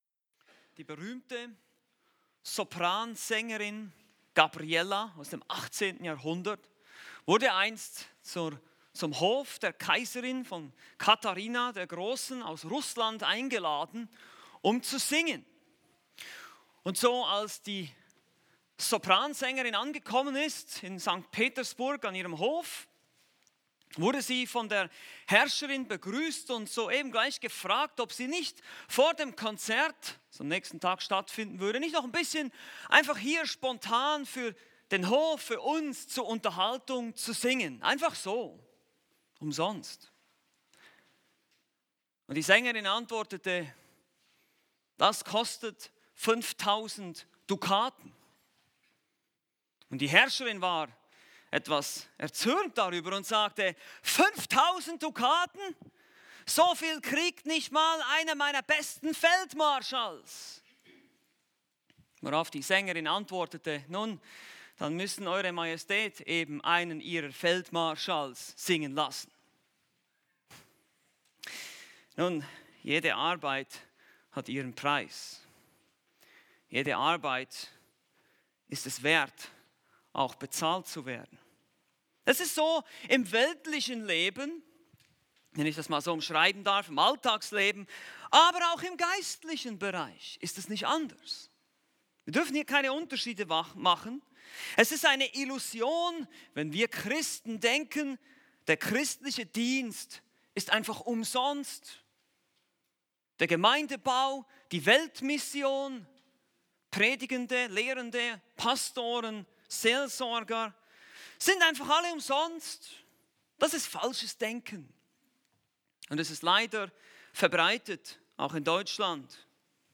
Predigten Übersicht nach Serien - Bibelgemeinde Berlin